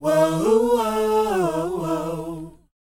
WHOA E C.wav